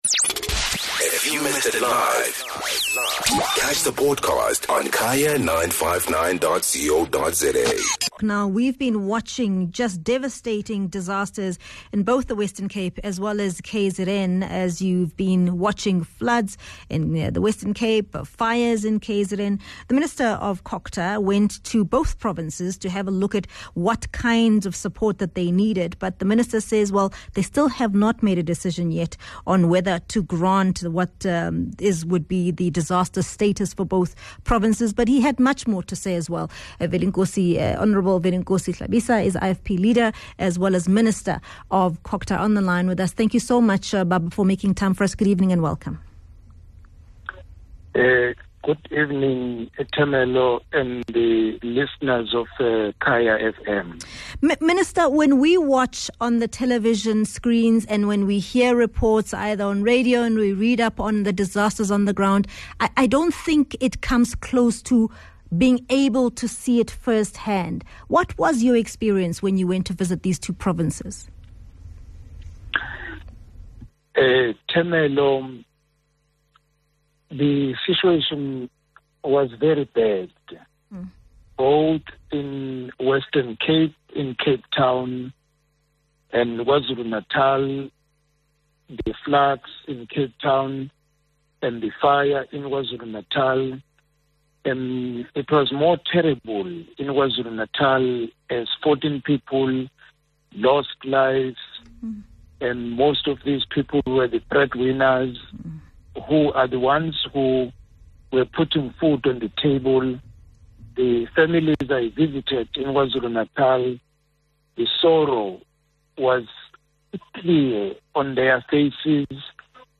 Guest: Hon. Velenkosi Hlabisa - COGTA Minister